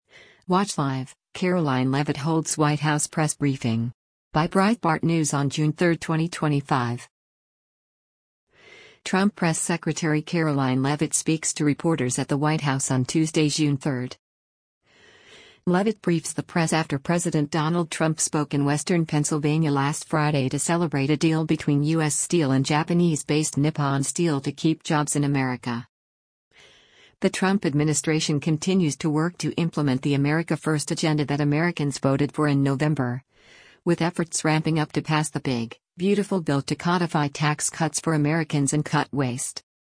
Trump Press Secretary Karoline Leavitt speaks to reporters at the White House on Tuesday, June 3.